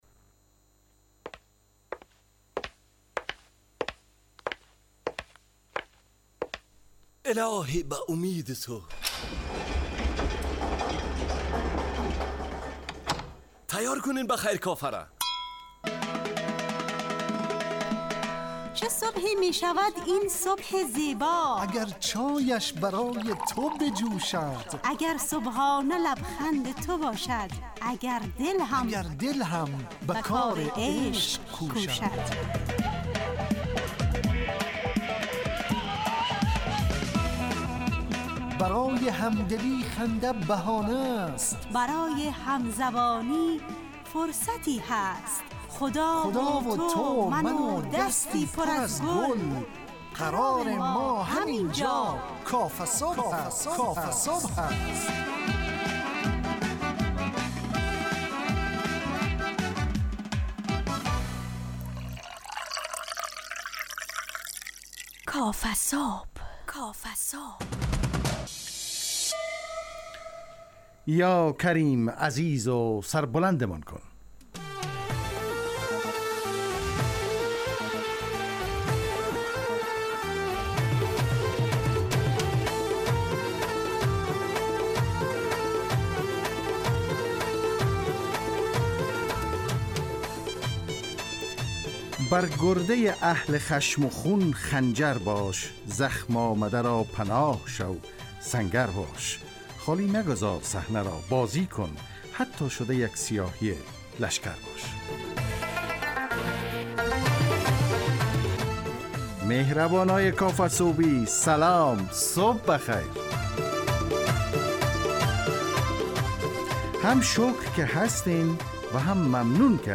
کافه‌صبح – مجله‌ی صبحگاهی رادیو دری با هدف ایجاد فضای شاد و پرنشاط صبحگاهی همراه با طرح موضوعات اجتماعی، فرهنگی و اقتصادی جامعه افغانستان با بخش‌های کارشناسی، هواشناسی، نگاهی به سایت‌ها، گزارش، گپ صبح، صبح جامعه و صداها و پیام‌ها شنونده‌های عزیز